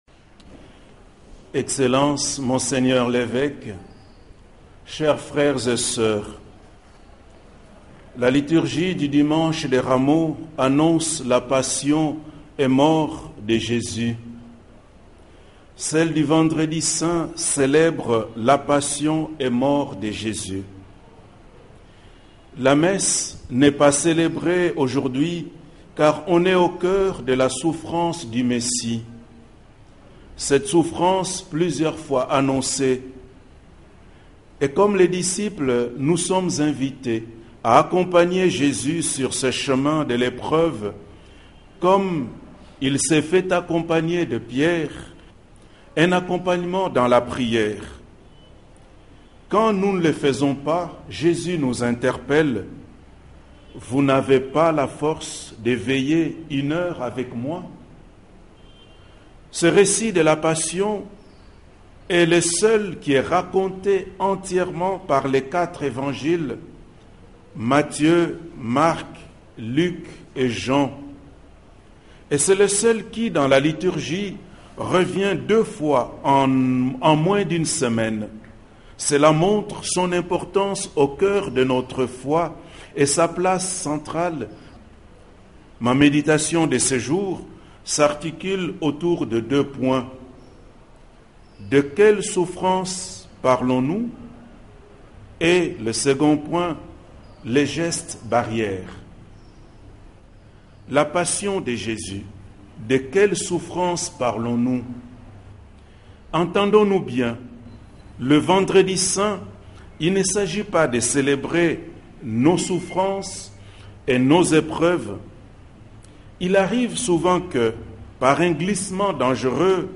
Homelie vendredi saint 2020
Bien que célébré en mode confinement, toute la liturgie de cet office a été respectée : la prostration, la lecture de la passion, la prière universelle suivant les intentions prévues à cette occasion, la vénération de la croix ainsi que la communion.
homelie-vendredi-saint-2020.mpeg.mp3